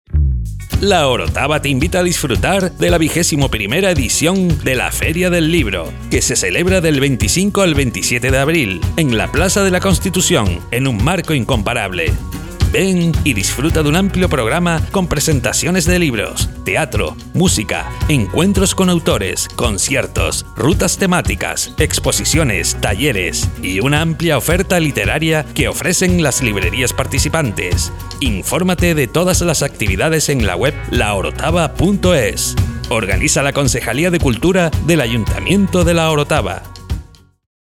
Cuña promocional